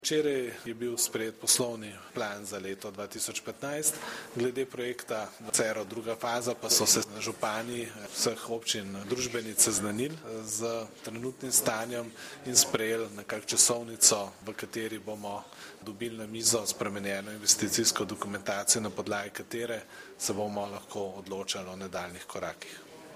Župan Gregor Macedoni o načrtu aktivnosti urejanja romskega naselja Žabjak